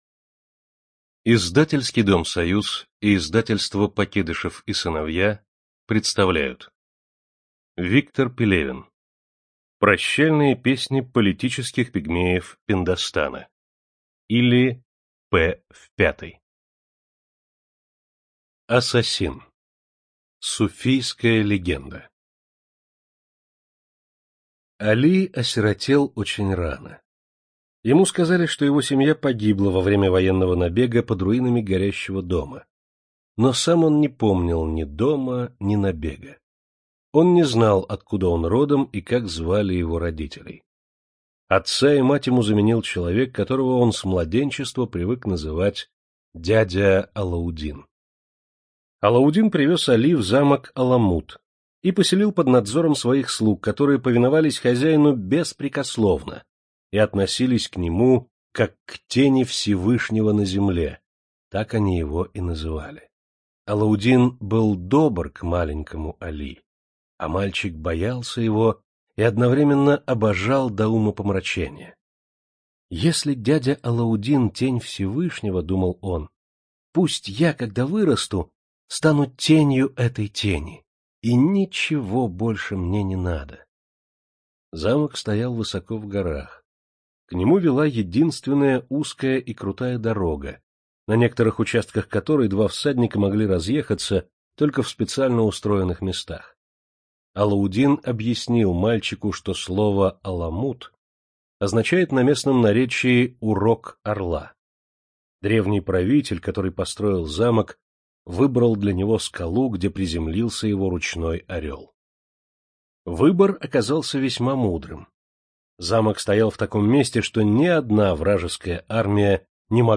Студия звукозаписиСоюз